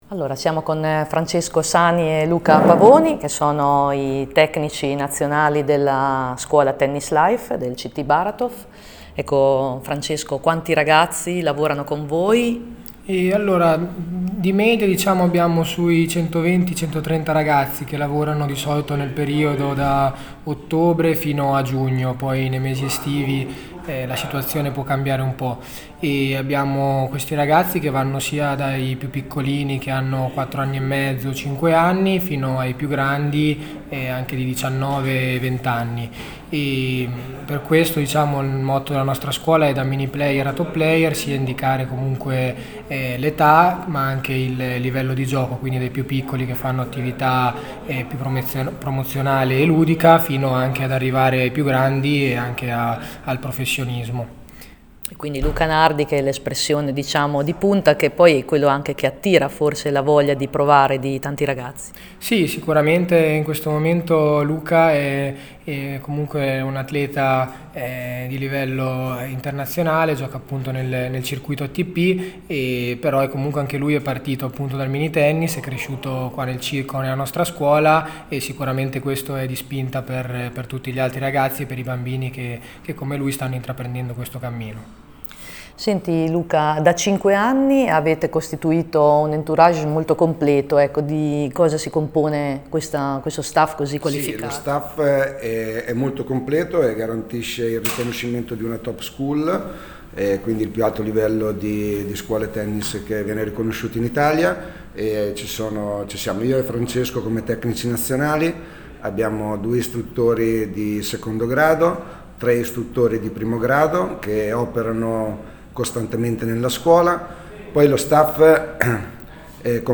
Unico circolo nelle Marche ad avere due squadre di serie A, maschile e femminile, con un florido vivaio alle spalle. Ecco l’intervista